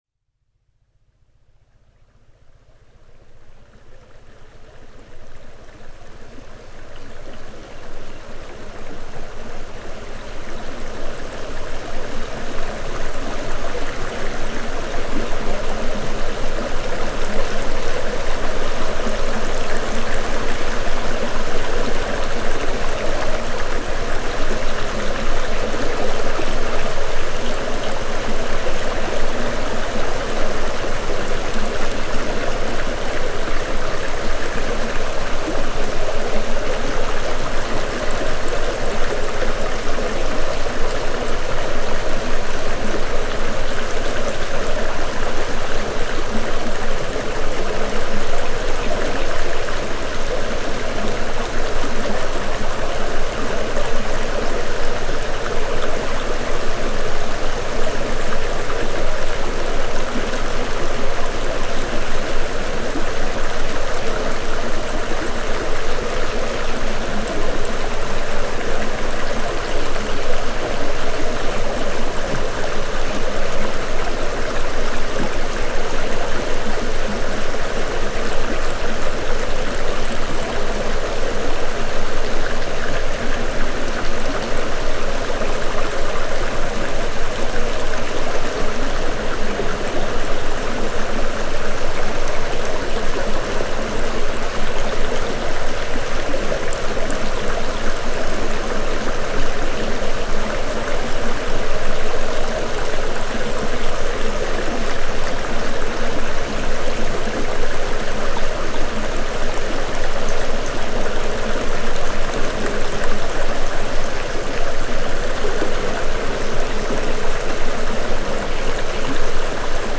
Under the surface, multiple layers of audible and inaudible tones, frequencies, and energies work synergistically with your brain, opening up new neural pathways, nudging existing ones, and facilitating profound coherence in your system.
It’s a pleasant audio experience, but it’s not like music or even white noise. (Don't worry, there are no new-agey harps or synthesizers that get boring.)
Don’t be fooled – this is not just nature sounds – it is the design and technology infused in the soundtrack that evokes the energetic and cognitive sharpening response.